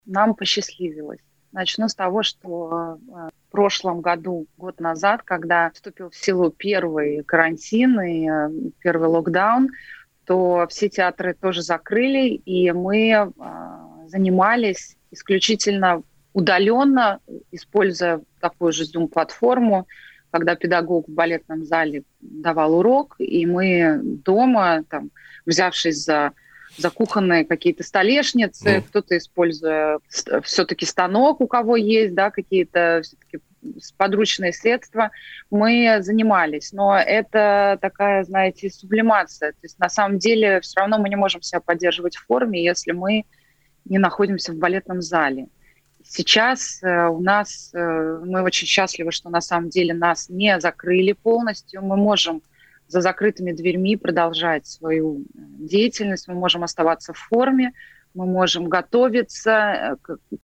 4-balerina-o-repetitsiyah-v-zale-1.mp3